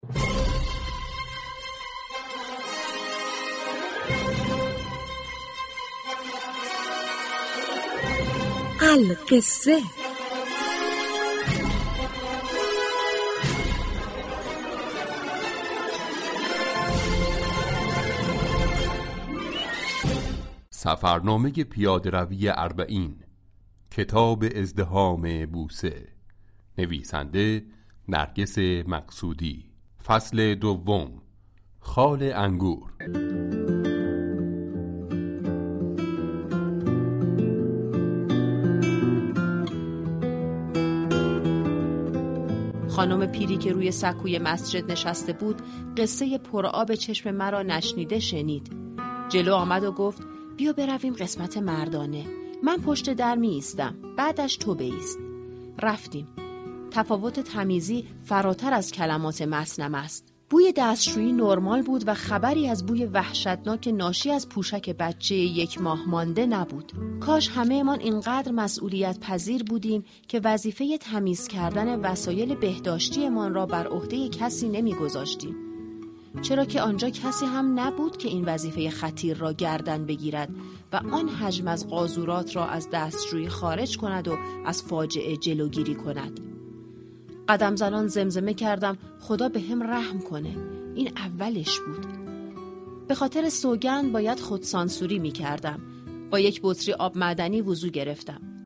این برنامه كه به گویندگی مخاطبان برگزیده رادیو صبا در چالش گویندگی تهیه و تولید می شود، كه به مناسبت اربعین حسینی كتاب صوتی «ازدحام بوسه» را تقدیم شنوندگان می كند.
گفتنی است«ازدحام بوسه» ششمین كتاب صوتی است كه به گویندگی مخاطبان برگزیده رادیو صبا تولید و پخش می شود.